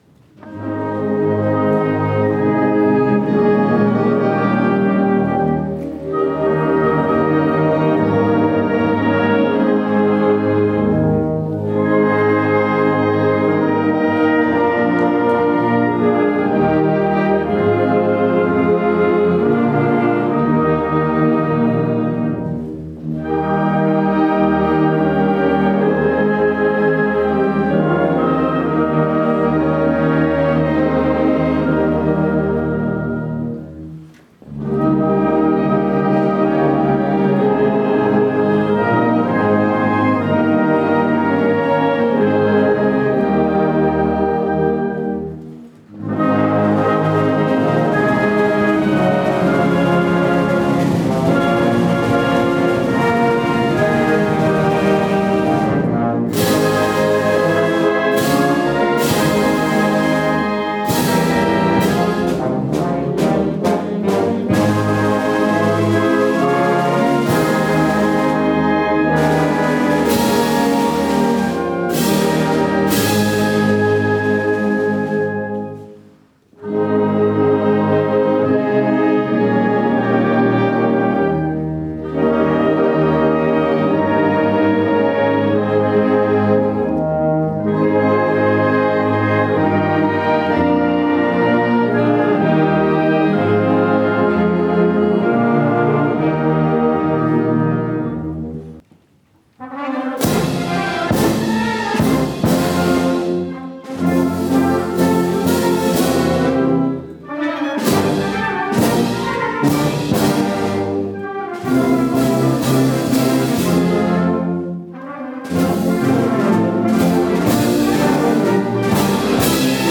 Ouça trechos dos hinos nacionais da Suíça e do Brasil executados pela Campesina na comemoração do dia 16 de maio de 2013, na Suíça.